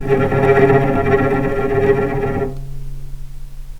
healing-soundscapes/Sound Banks/HSS_OP_Pack/Strings/cello/tremolo/vc_trm-C#3-pp.aif at 01ef1558cb71fd5ac0c09b723e26d76a8e1b755c
vc_trm-C#3-pp.aif